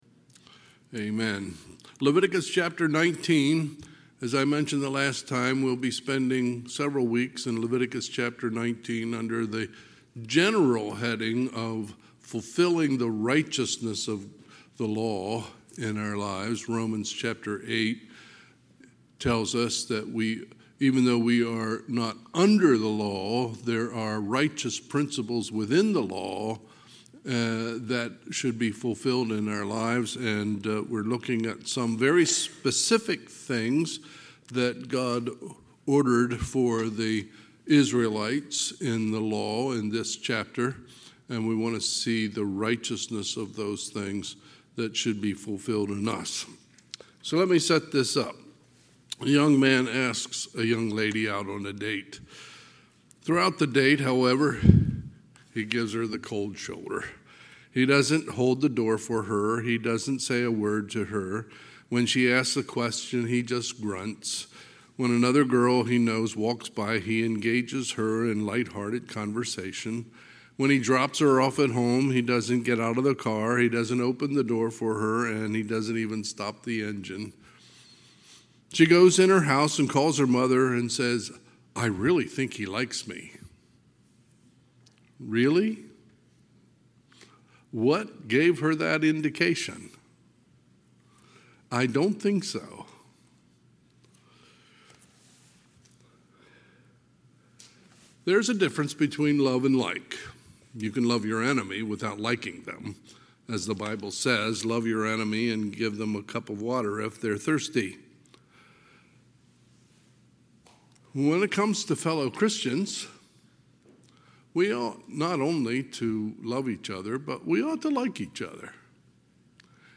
Sunday, August 11, 2024 – Sunday PM